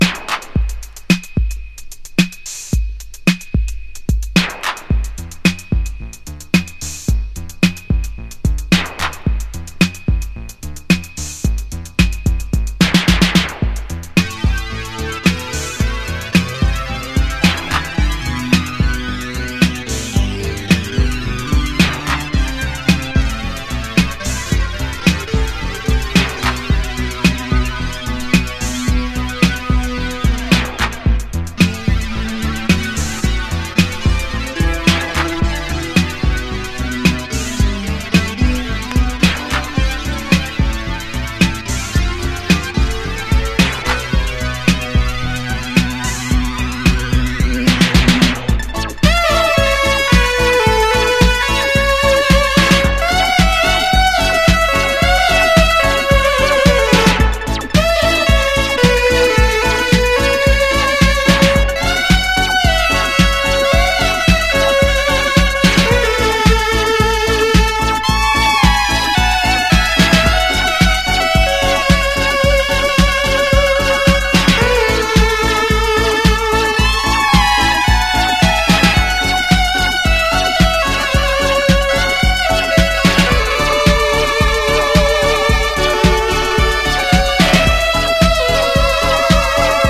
どこか控えめな音作りでB、C級の位置づけのアルバムですが、今となってはそこがいい！